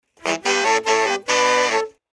大喇叭02
描述：13个大喇叭的循环。
标签： 115 bpm Funk Loops Woodwind Loops 719.07 KB wav Key : Unknown
声道立体声